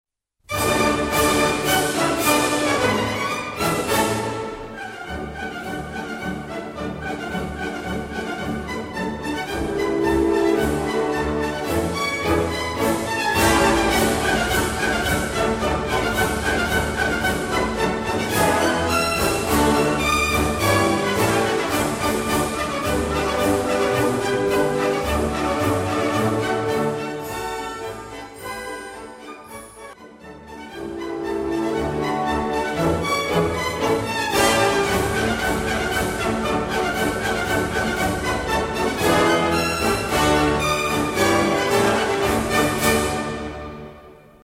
内容解説 最も有名な代表的クラシカルマーチです
編成内容 大太鼓、中太鼓、小太鼓、シンバル、トリオ、デュォ 作成No ０８７